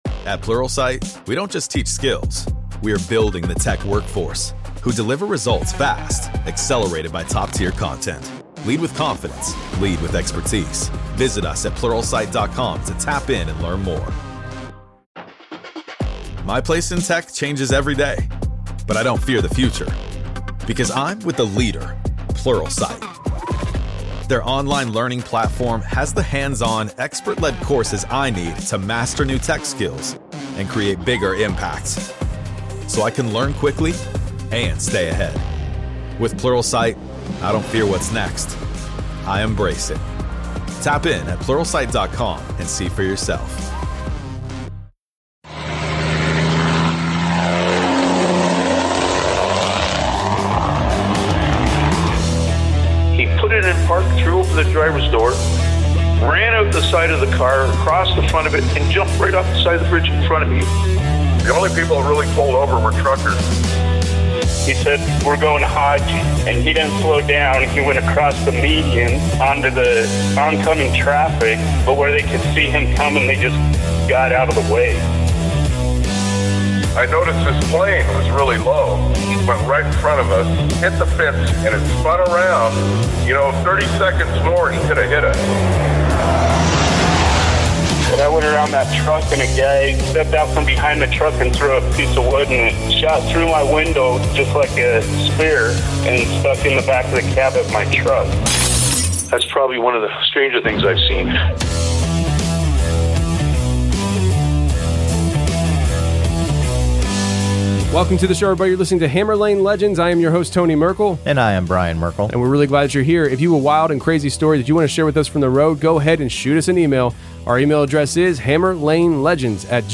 a trucker of over 13 years.